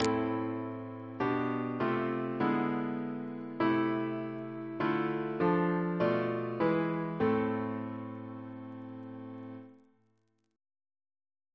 Single chant in A minor Composer: Leopold Lancaster Dix (1861-1935) Reference psalters: ACB: 198